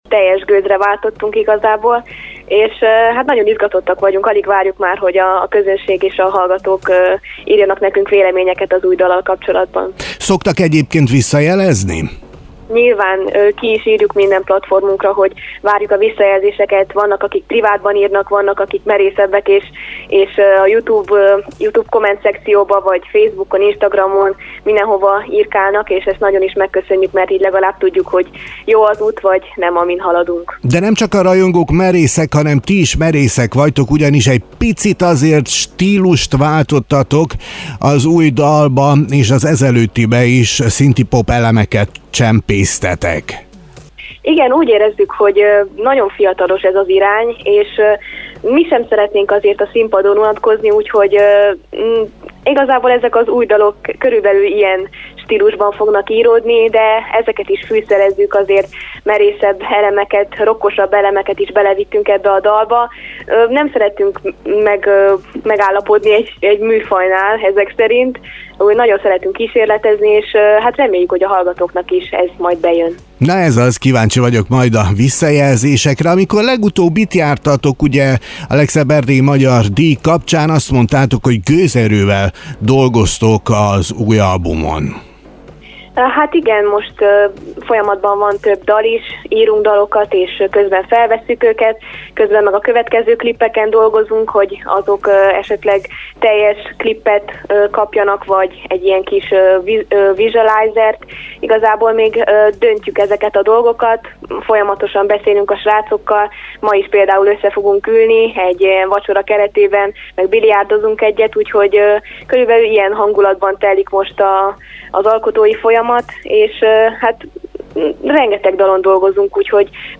dreamflow-robbanasveszely-interju.mp3